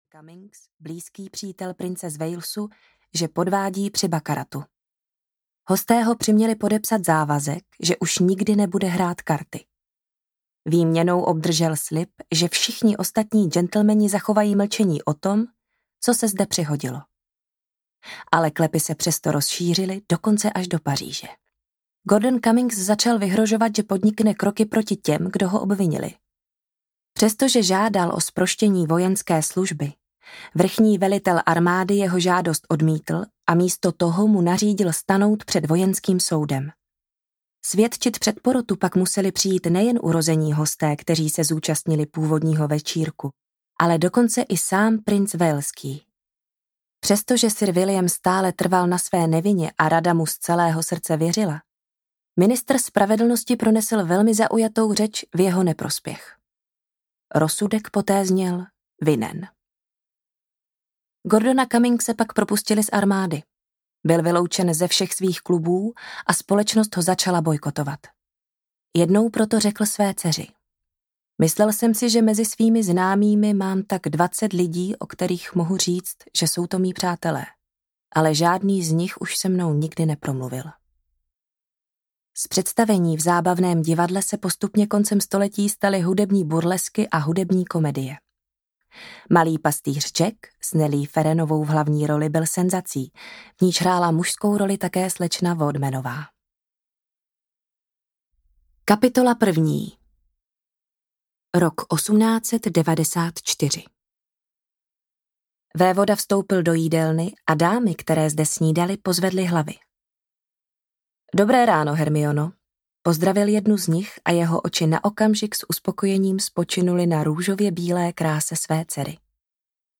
Závod o lásku audiokniha
Ukázka z knihy